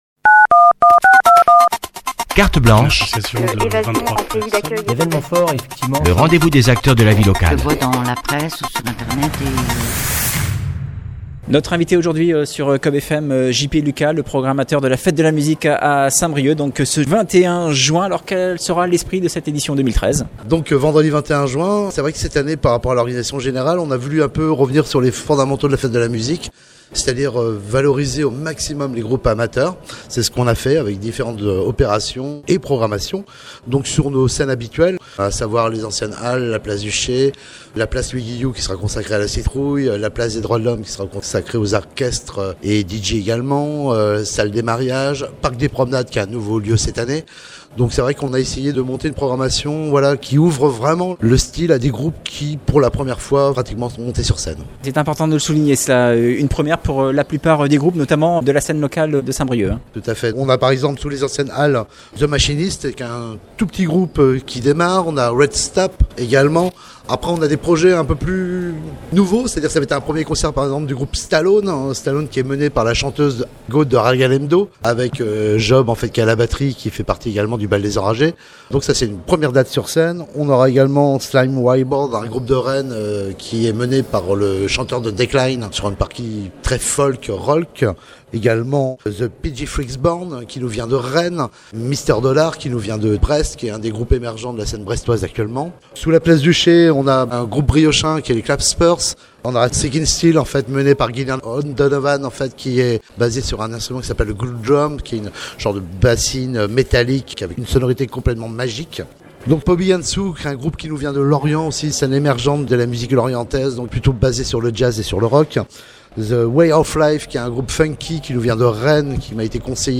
Tweet Cette entrée a été publiée dans Interviews .